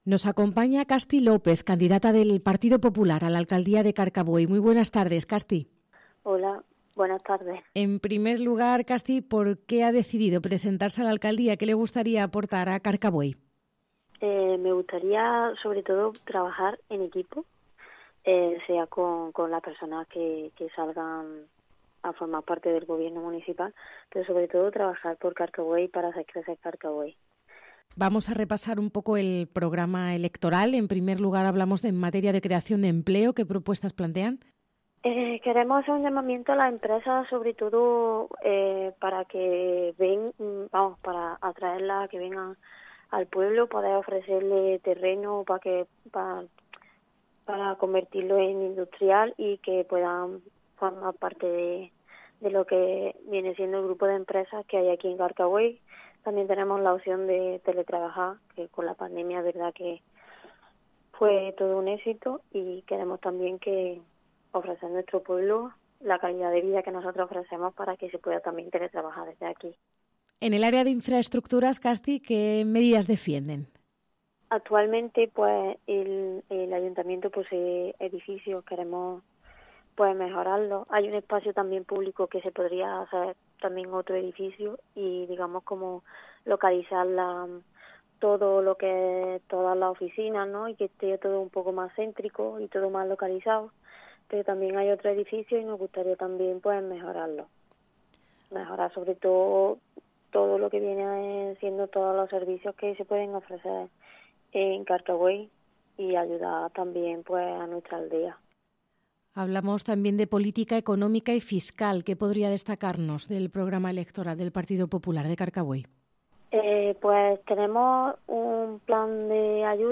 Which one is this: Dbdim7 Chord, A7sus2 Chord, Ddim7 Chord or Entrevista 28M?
Entrevista 28M